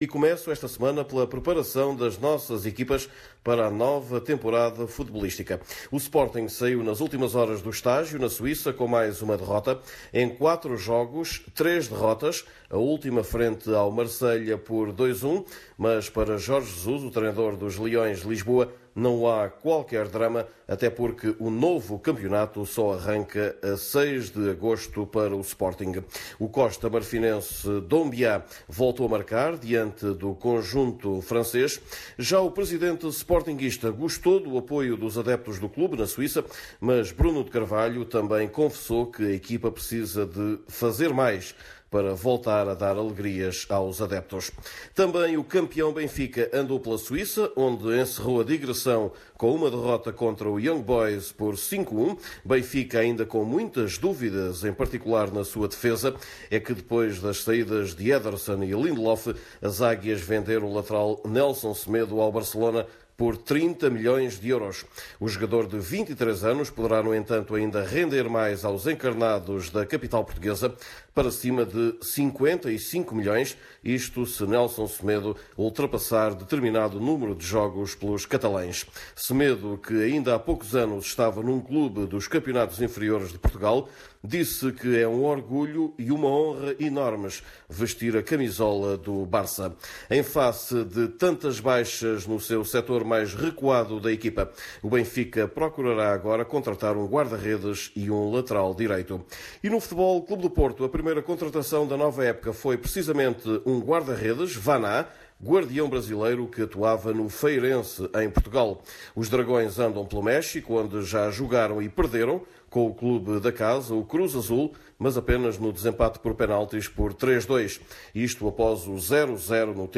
Neste boletim semanal, conheça ainda alguns dos resultados das modalidades lusas.